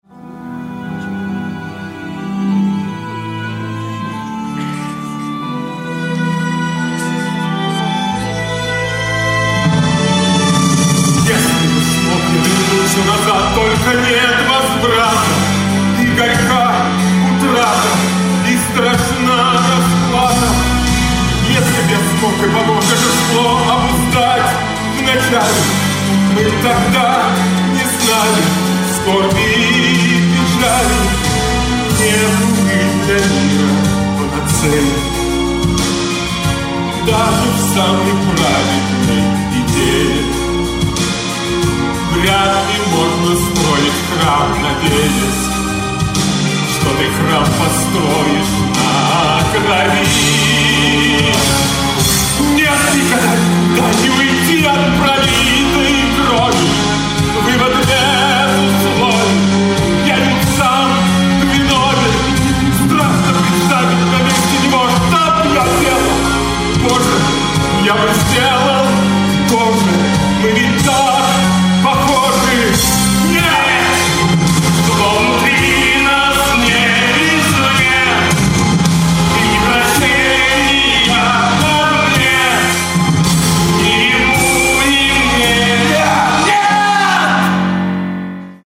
Музыкальная драма в 2-х частях
Аудио запись со спектакля от 26.05.2005 г*.